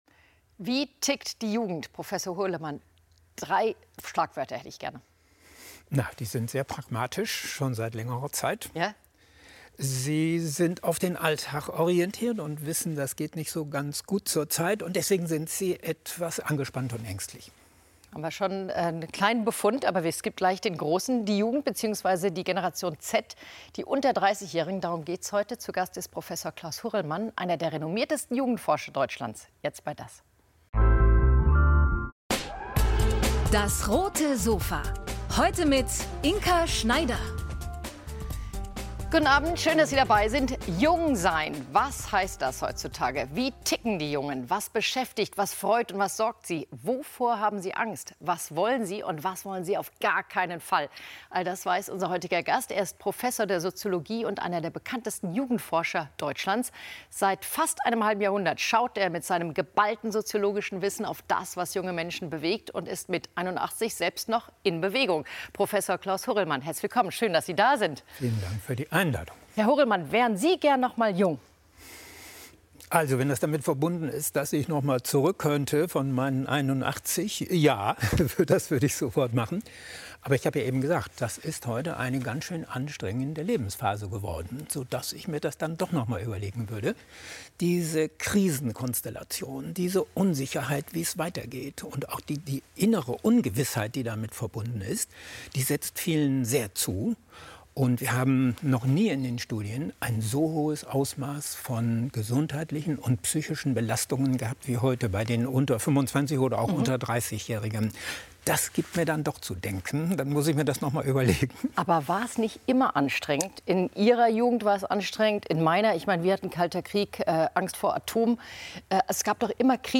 Sozialwissenschaftler Prof. Dr. Klaus Hurrelmann erforscht seit über 50 Jahren Jugendliche und junge Erwachsene.